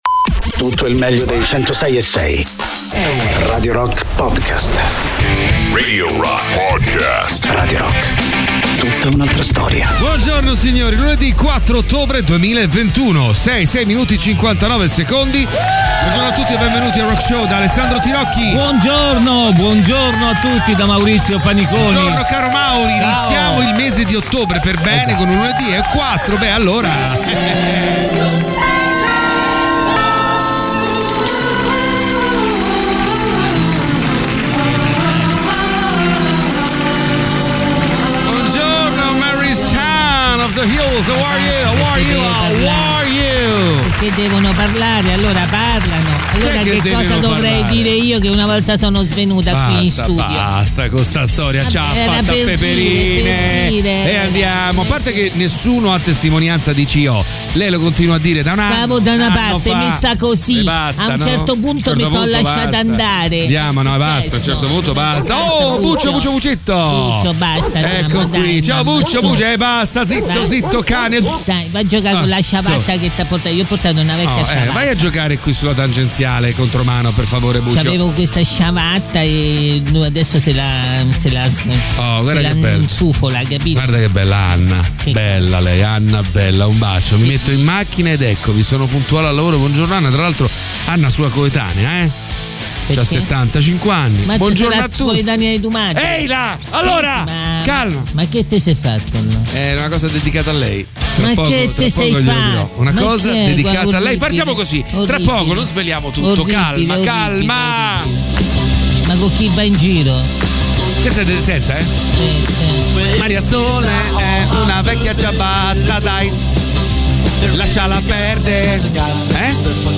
in diretta dal lunedì al venerdì